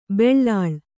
♪ beḷḷāḷ